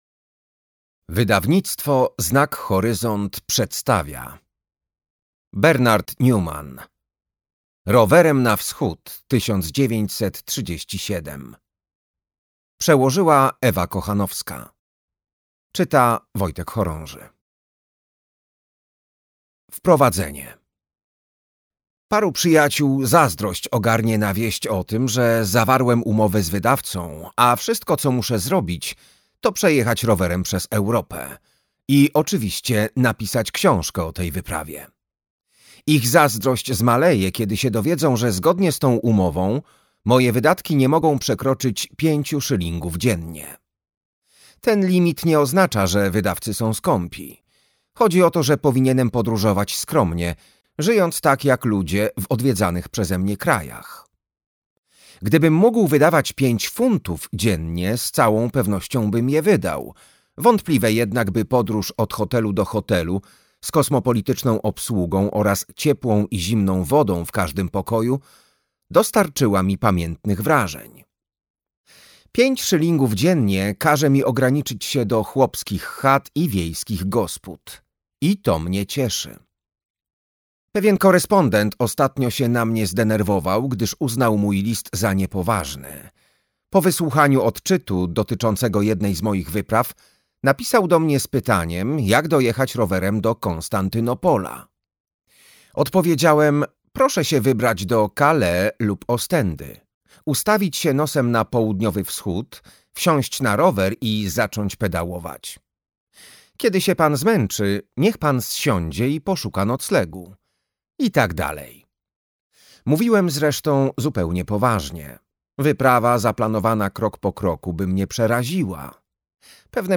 Rowerem na Wschód 1937 - Bernard Newman - audiobook + książka